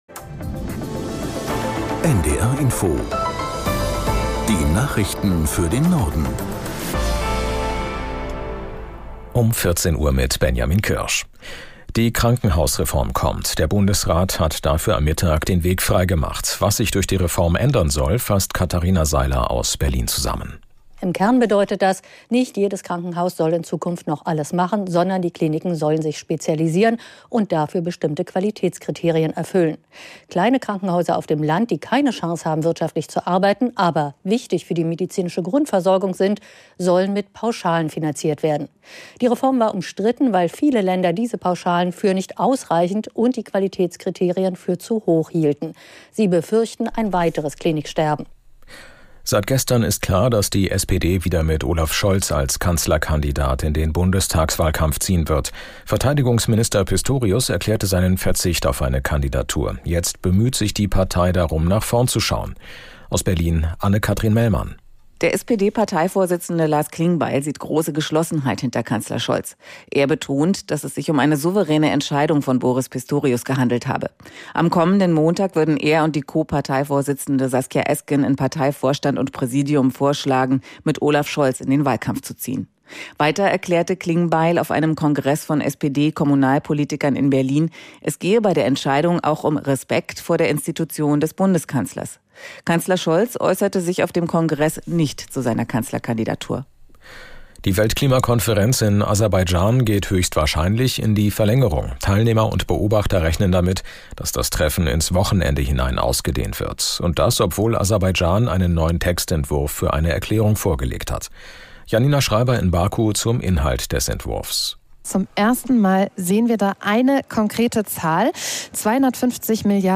Nachrichten.